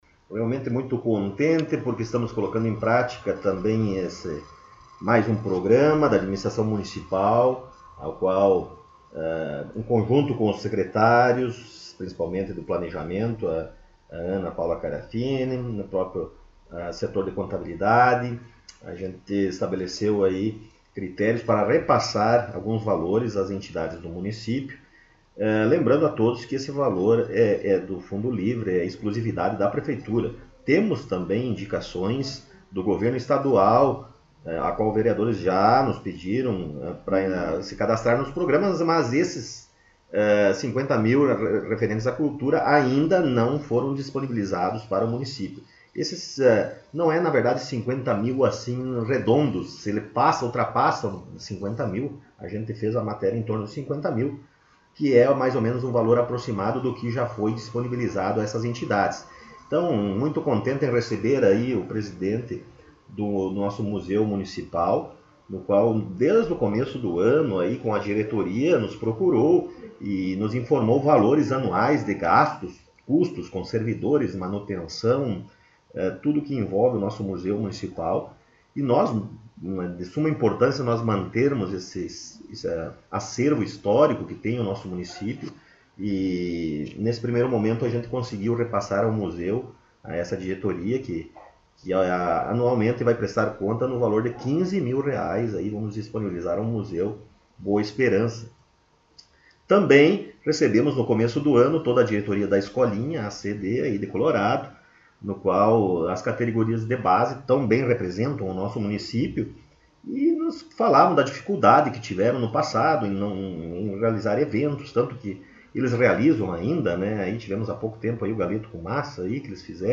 O Colorado em Foco esteve no Gabinete da Prefeitura Municipal para entrevistar o Prefeito Rodrigo e saber das últimas informações de trabalhos, atividades e programas do Governo Municipal.